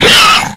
flesh_attack_2.ogg